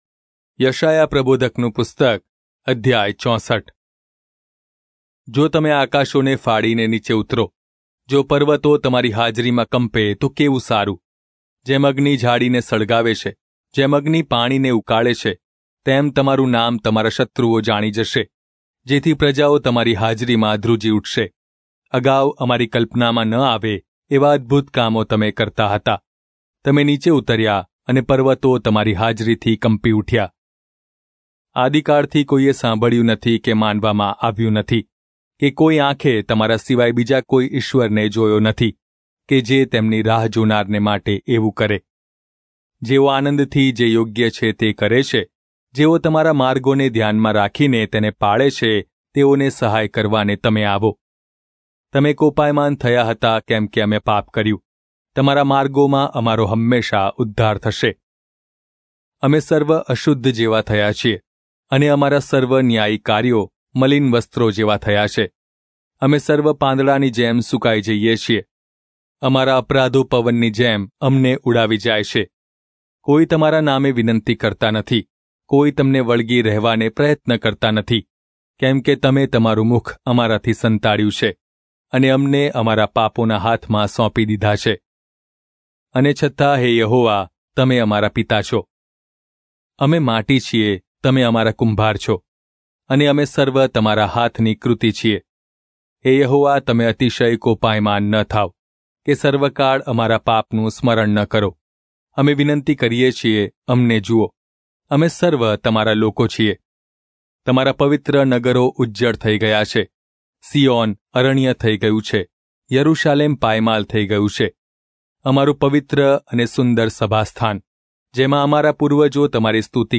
Gujarati Audio Bible - Isaiah 32 in Irvgu bible version